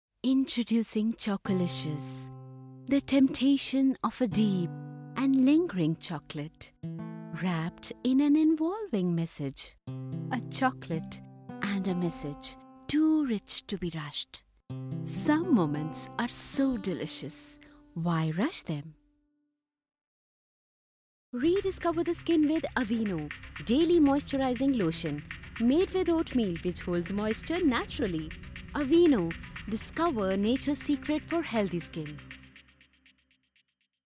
Female
My voice is pleasant , soft and compassionate.
Studio Quality Sample
English Ads